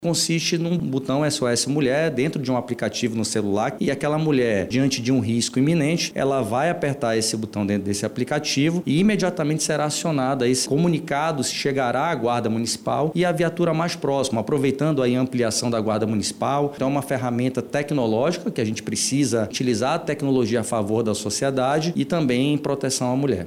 O autor do Projeto de Lei, o vereador Rodrigo Sá, explica de que forma a ferramenta vai funcionar, caso o projeto seja aprovado pelo Parlamento Municipal.